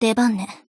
贡献 ） 协议：Copyright，其他分类： 分类:爱慕织姬语音 您不可以覆盖此文件。